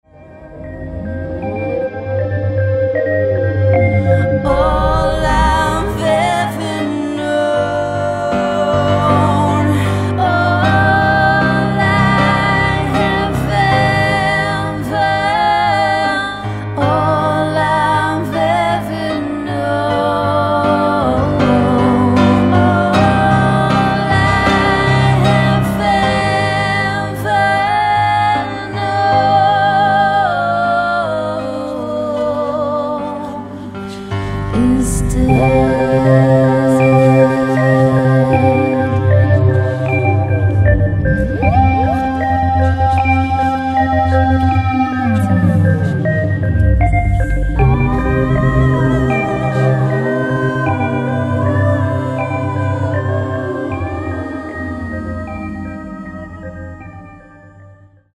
With powerful and emotional melodies
heavy and explosif rifs and rhythms
metal and rock
vocals, rhythm guitar
lead guitar
keyboards
bass